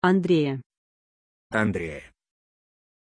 Pronunciación de Andrée
pronunciation-andrée-ru.mp3